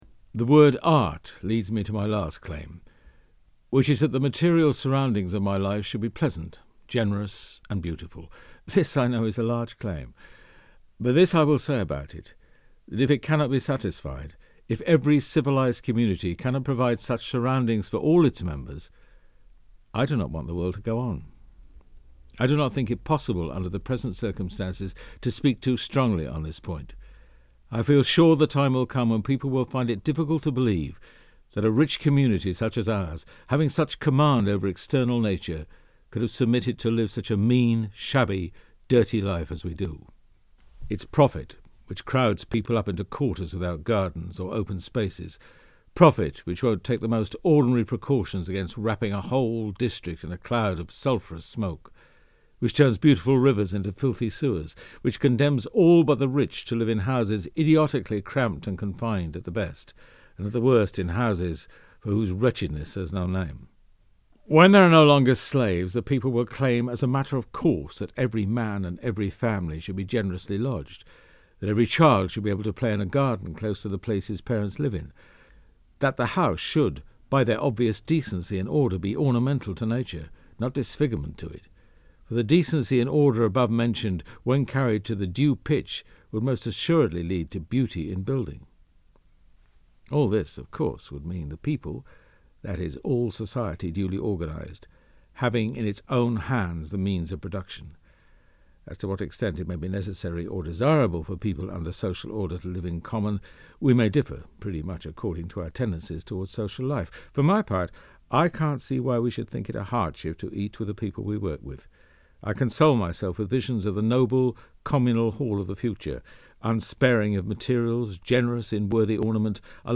Voice Reel